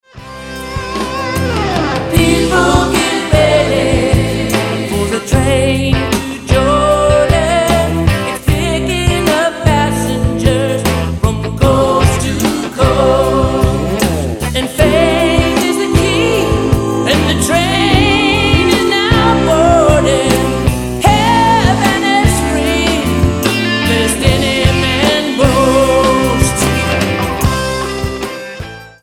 STYLE: Jesus Music